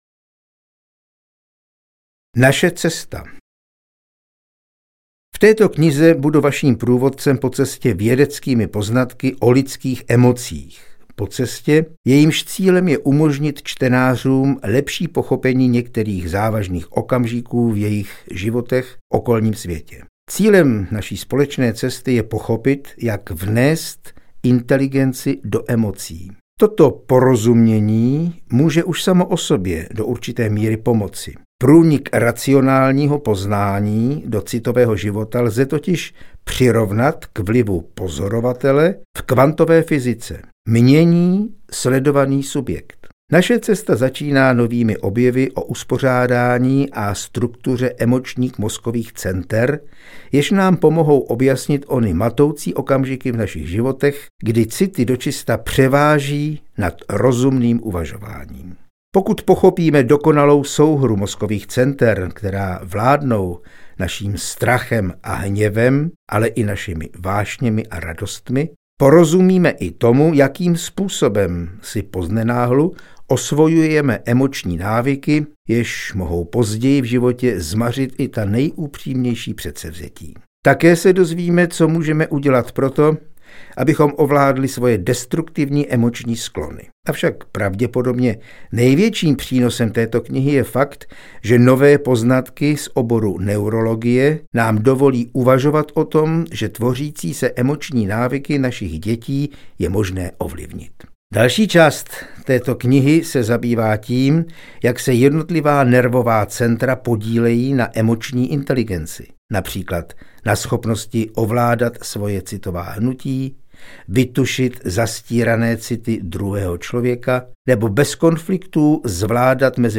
Emoční inteligence audiokniha
Ukázka z knihy